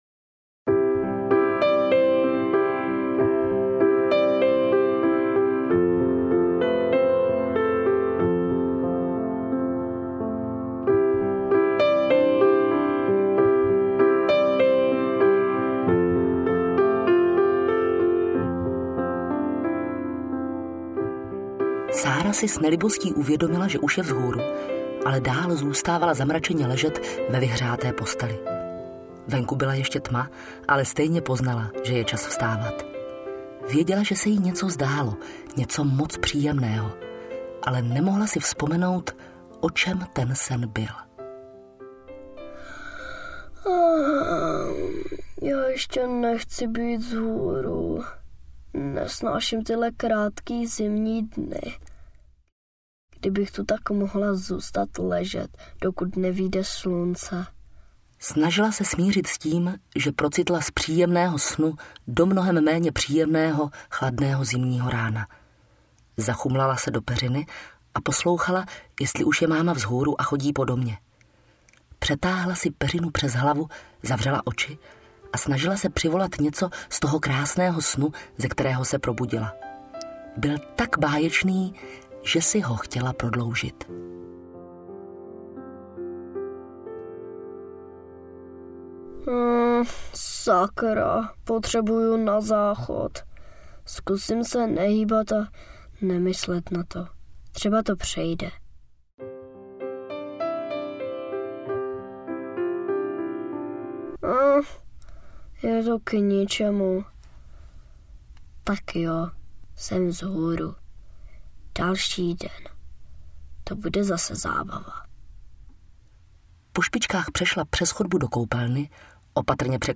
Ukázka z knihy
sara-kniha-prvni-audiokniha